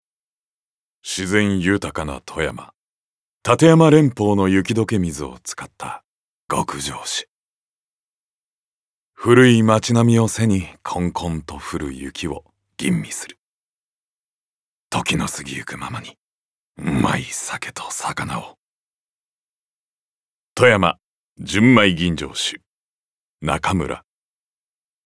ボイスサンプル
ナレーション３